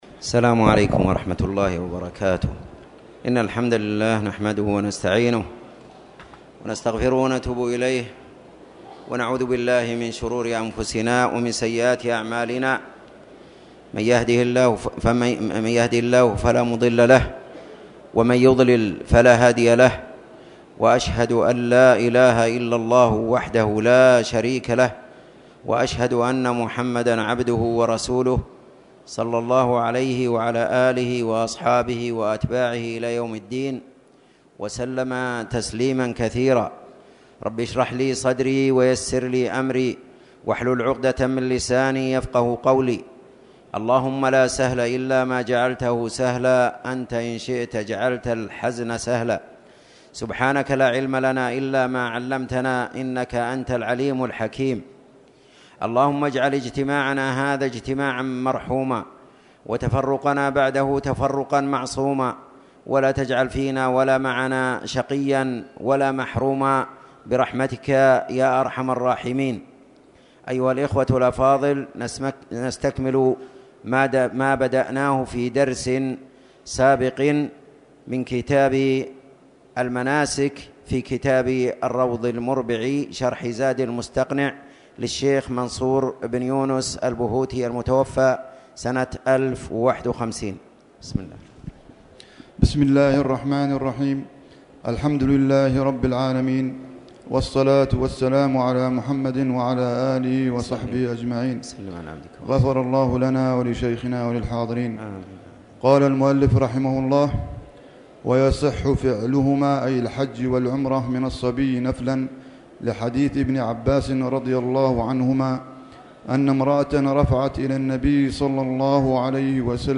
تاريخ النشر ٨ جمادى الآخرة ١٤٣٨ هـ المكان: المسجد الحرام الشيخ